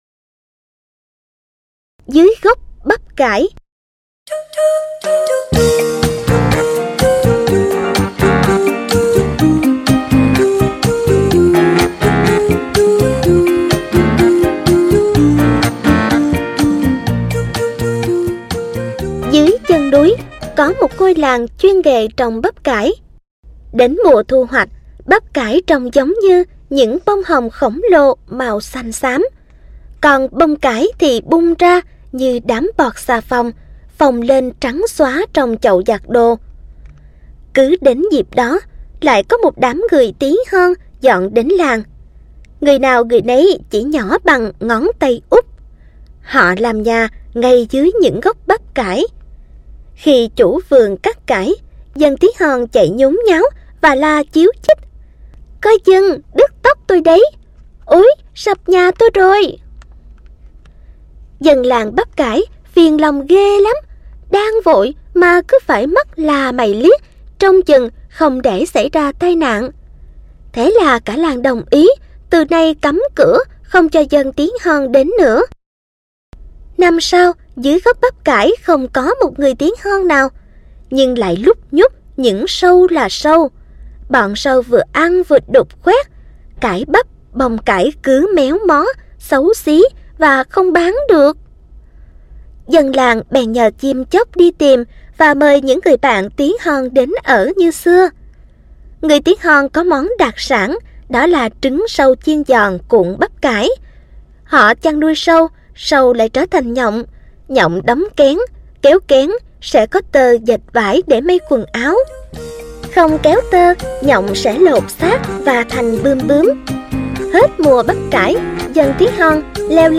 Sách nói | Xóm Đồ Chơi P14